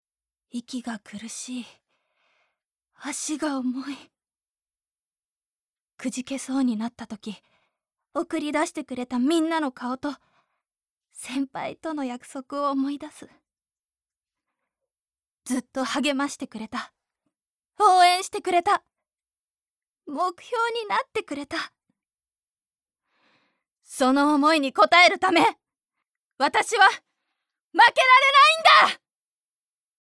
ボイスサンプル
頑張る女子